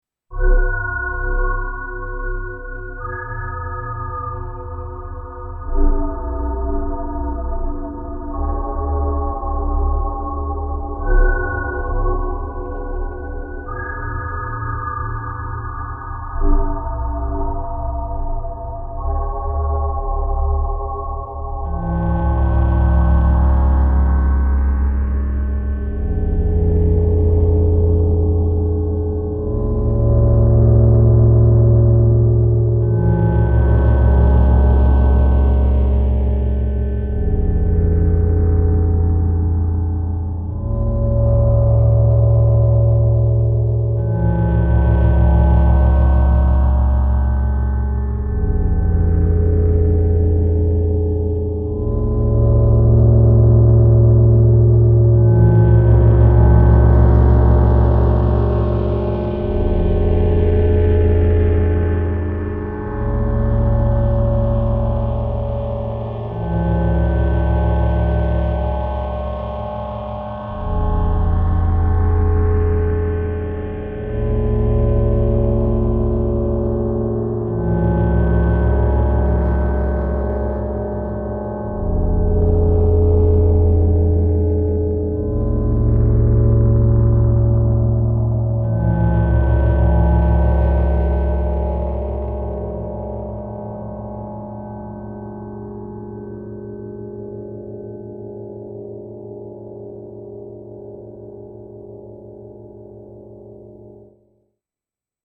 I went for quantity over quality this time :sweat_smile: This is all over the place, but quite firmly in the melodic/tonal camp.
Outer space travels with Waldorf Wave-like ambient textures, moving into Radiophonic workshop territory.